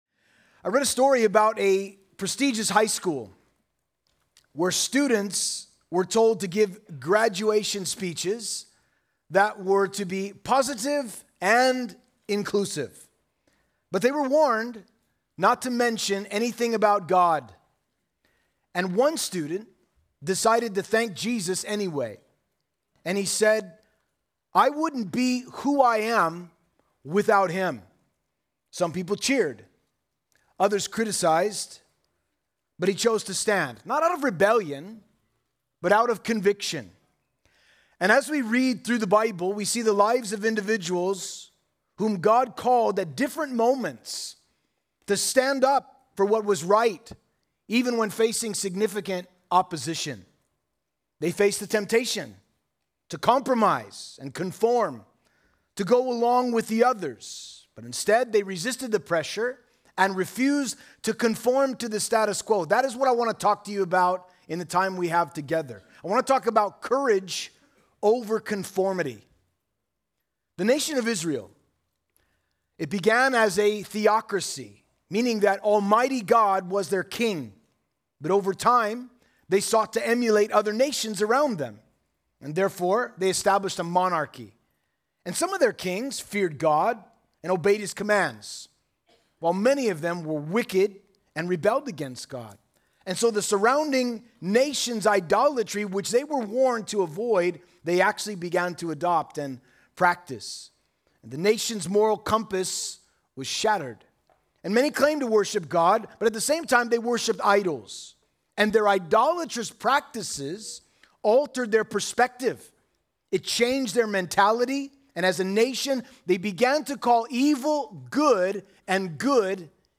Home » Sermons » Courage Over Conformity
Conference: Youth Conference